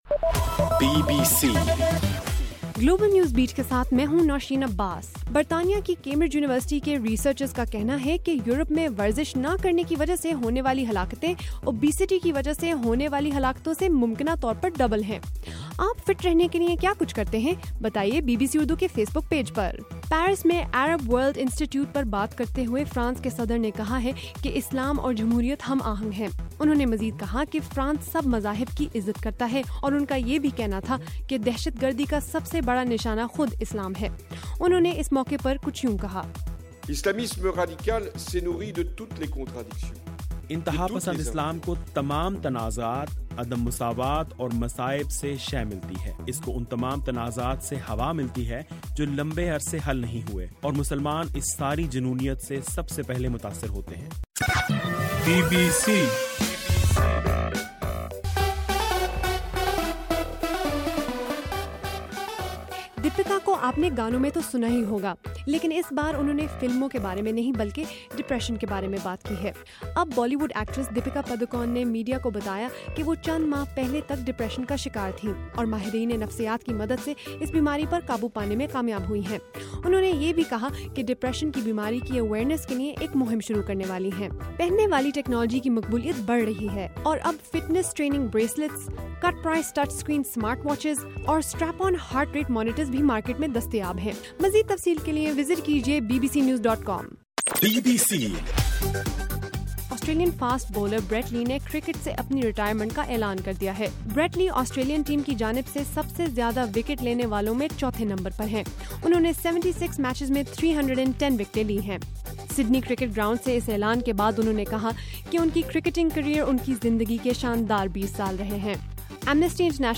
جنوری 15: رات 8 بجے کا گلوبل نیوز بیٹ بُلیٹن